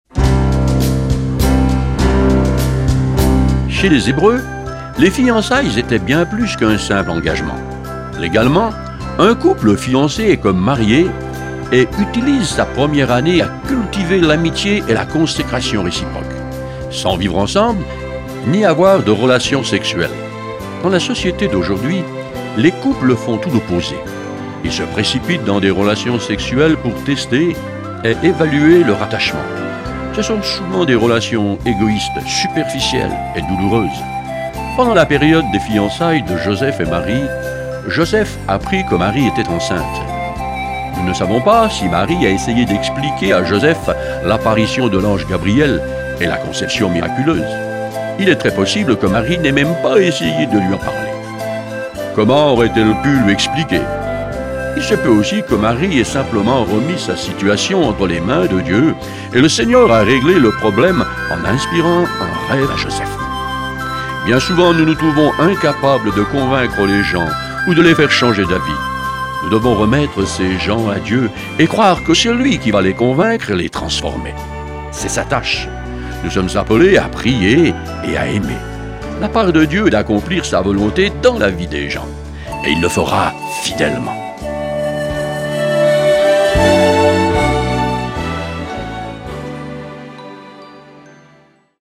Une série de méditations pour le mois de Décembre